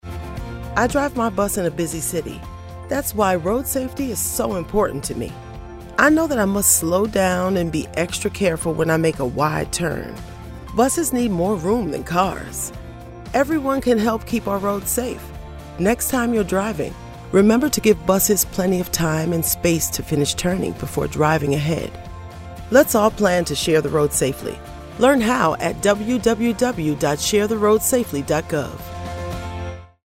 Audio Public Service Announcements (PSAs)